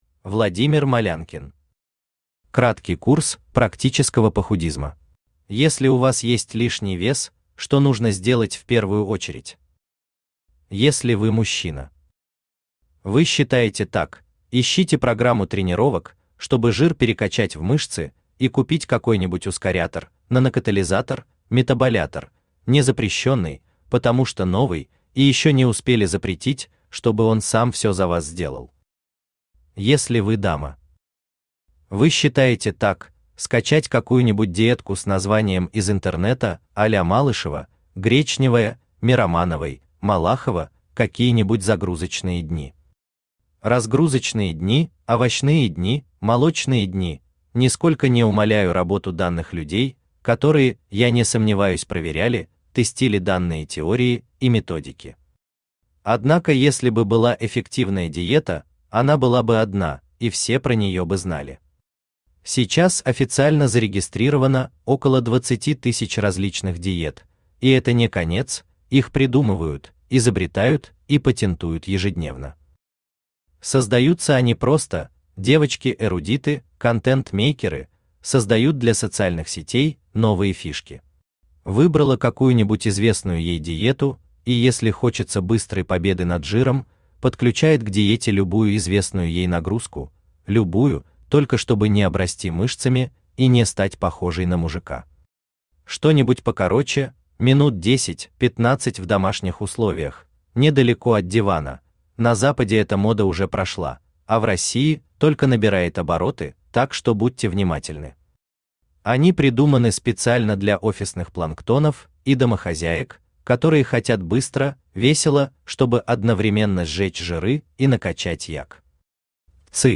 Аудиокнига Краткий курс практического похудизма | Библиотека аудиокниг
Aудиокнига Краткий курс практического похудизма Автор Владимир Юрьевич Малянкин Читает аудиокнигу Авточтец ЛитРес.